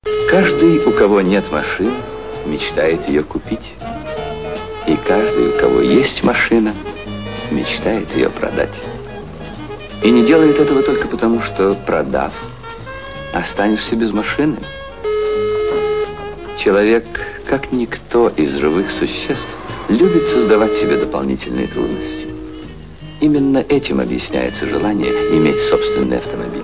Машина + Гудки